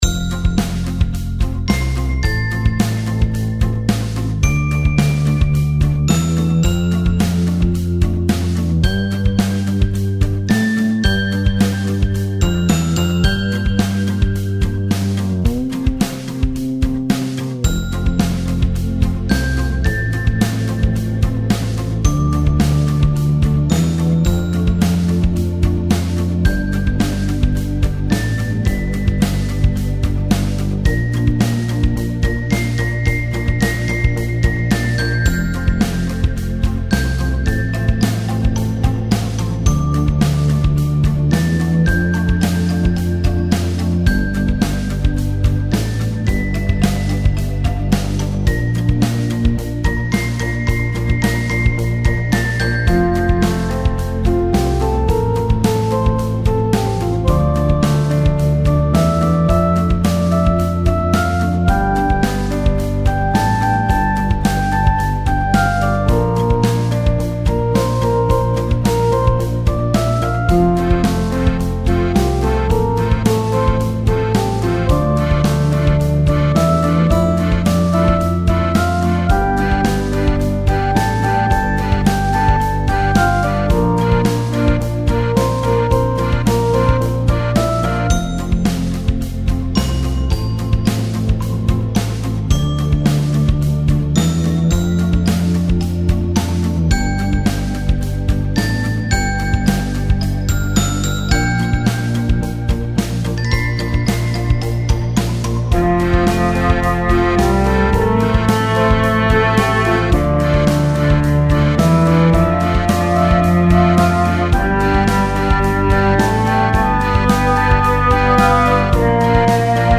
かわいらしく温かいファンタジー曲 です。
軽やかなリズムに柔らかい音色が重なり、アニメ・ゲームの村エリアや、のんびりした日常シーンにぴったり。
テンポは落ち着いていて聴き疲れしないため、動画の背景や作業BGMとしても使いやすいループ仕様になっています。
• ぽろんと鳴る可愛いメロディは「小人が道具をならす音」
• 柔らかなパッドは「森に差し込む光」
• テンポはゆったり、揺らぎのあるリズム感
• 高音域の軽いベル系サウンド
• 温かいパッドとアコースティック系の音色
フリーBGM ほのぼの アンビエント ファンタジー 癒し 自然 可愛い 小人 日常